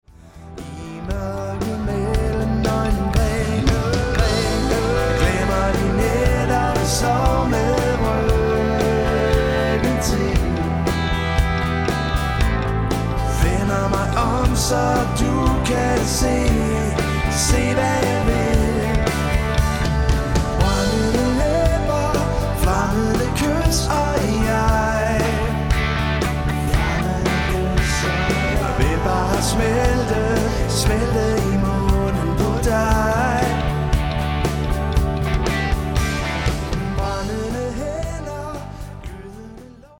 Professionel - Allround party band
• Coverband